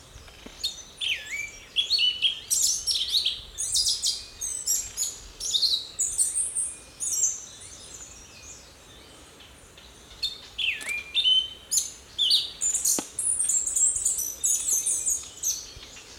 Andean Slaty Thrush (Turdus nigriceps)
Sex: Male
Life Stage: Adult
Location or protected area: Parque Nacional Calilegua
Condition: Wild
Certainty: Photographed, Recorded vocal